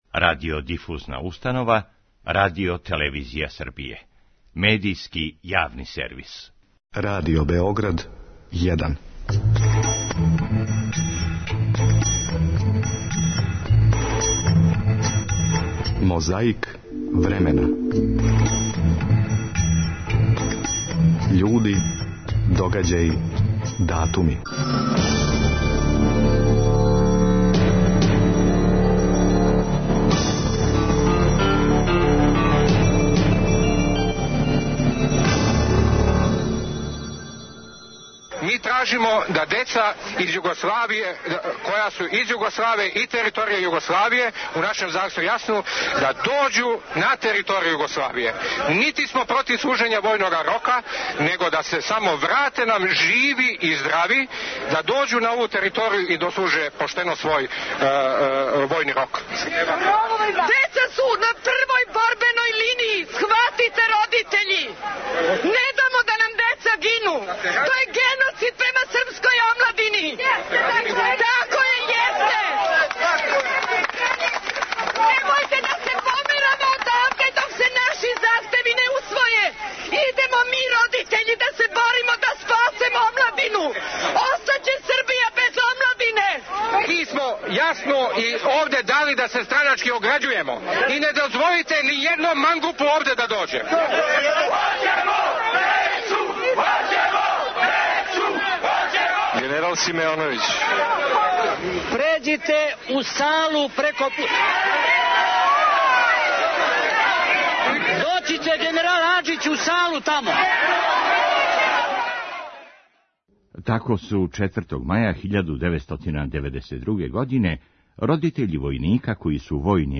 А маја 2000. за Радио Београд говорио је Глумац Миша Јанкетић.